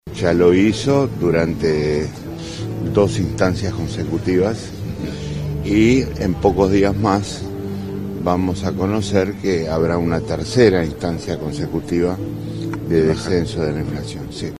En el marco de las recorridas por Fray Bentos, tras el Consejo de Ministros abierto, Astori fue consultado en rueda de prensa sobre la inflación de agosto, cuyos datos se conocerán los primeros días de setiembre: